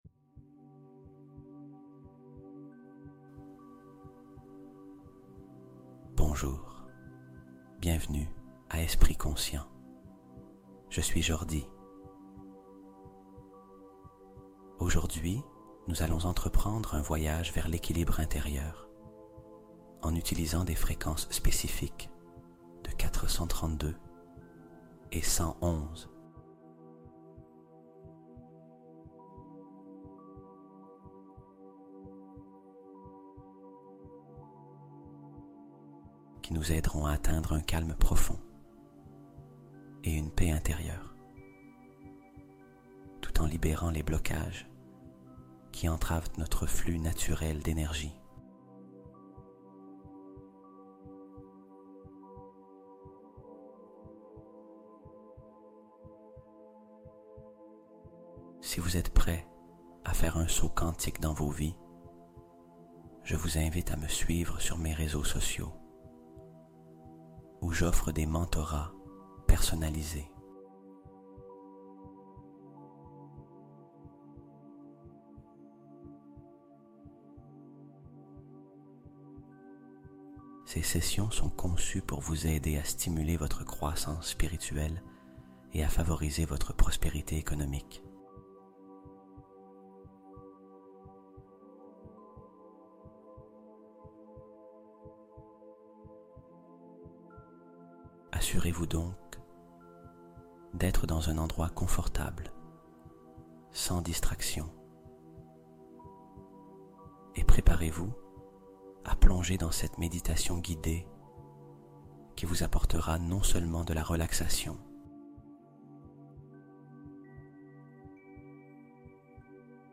Libération Totale : Dissoudre les blocages invisibles avec le 432 Hz